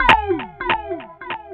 Ambi_Click_Down.wav